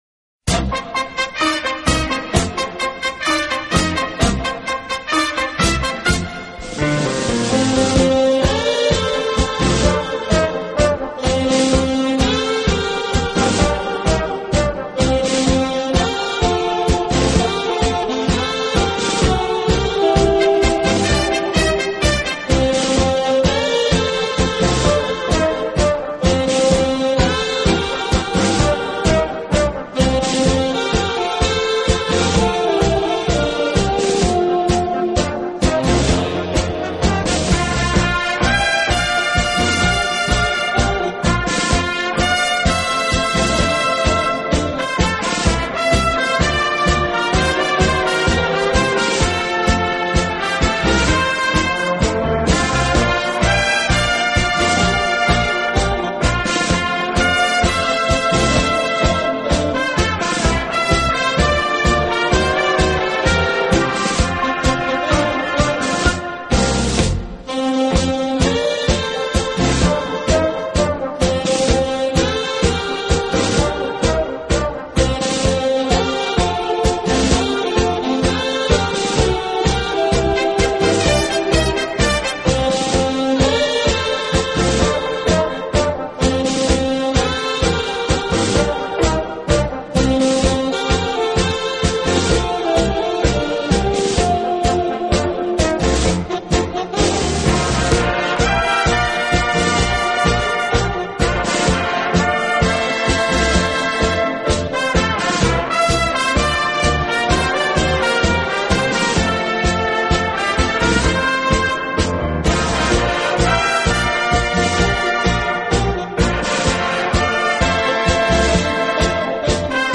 的轻音乐团，以萨克斯管为主，曲目多为欢快的舞曲及流行歌曲改编曲。
轻快、柔和、优美，带有浓郁的爵士风味。
代感的，乐而不狂，热而不躁，这支以萨克斯管为主体的乐队以鲜明的特征
风格，高雅而宁静，适合一个人静静聆听。